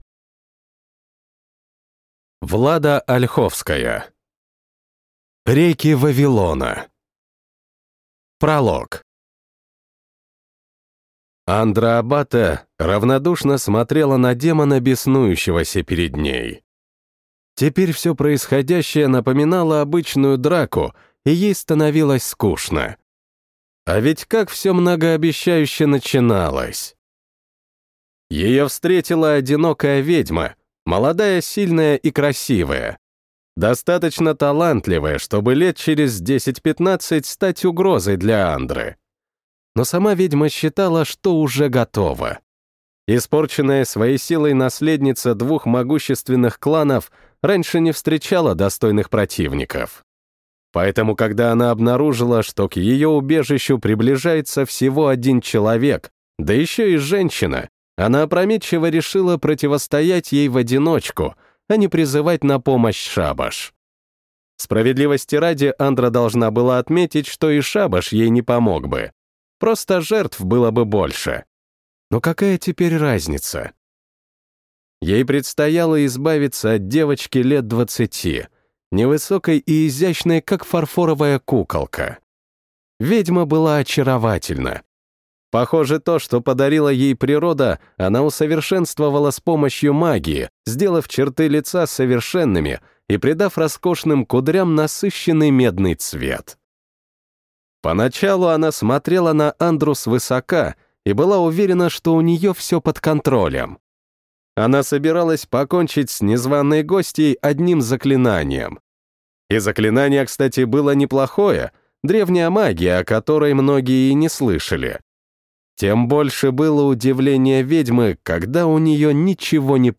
Оценщик. Поединщик поневоле (слушать аудиокнигу бесплатно) - автор Григорий Шаргородский